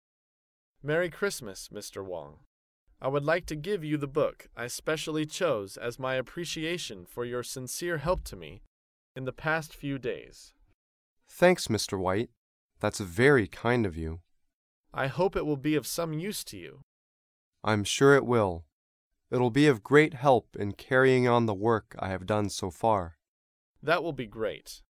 在线英语听力室高频英语口语对话 第346期:赠送书籍的听力文件下载,《高频英语口语对话》栏目包含了日常生活中经常使用的英语情景对话，是学习英语口语，能够帮助英语爱好者在听英语对话的过程中，积累英语口语习语知识，提高英语听说水平，并通过栏目中的中英文字幕和音频MP3文件，提高英语语感。